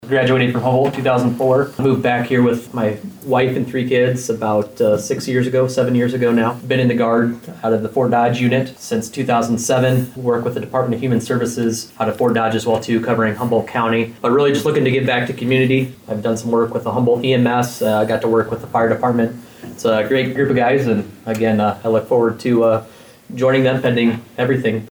On Monday at the Humboldt City Council meeting